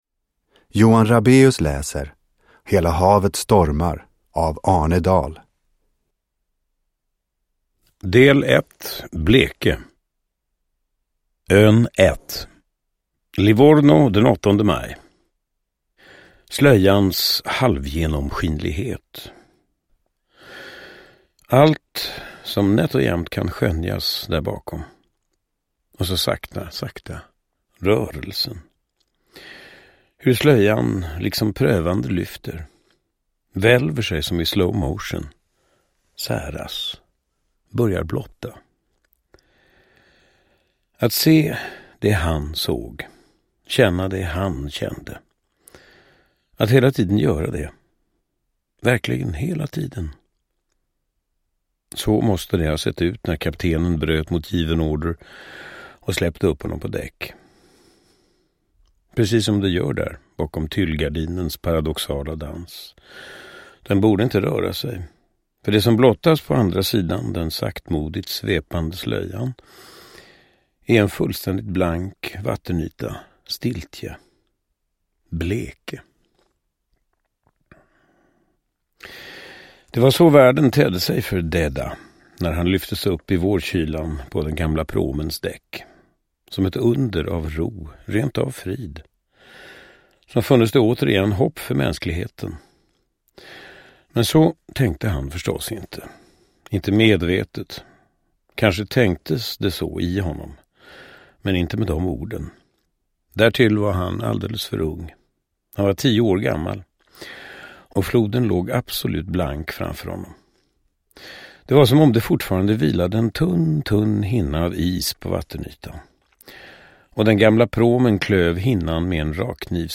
Uppläsare: Johan Rabaeus
Ljudbok